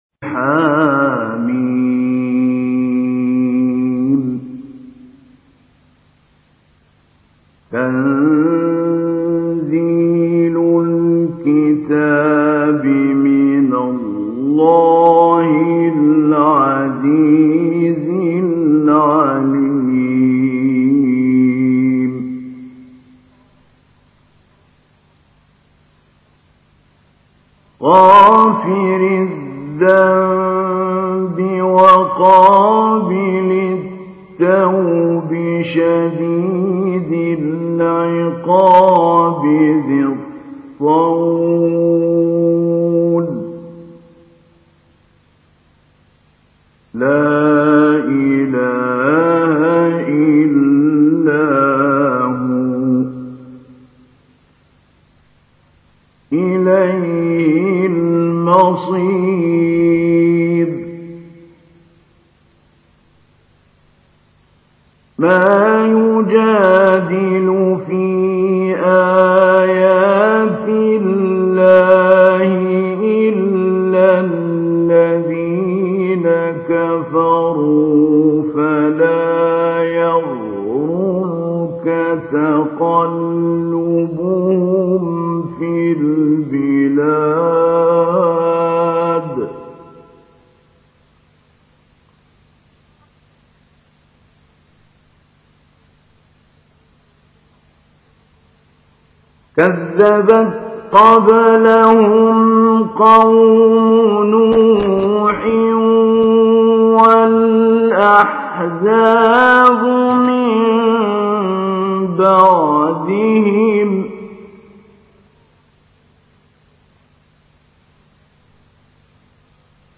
تحميل : 40. سورة غافر / القارئ محمود علي البنا / القرآن الكريم / موقع يا حسين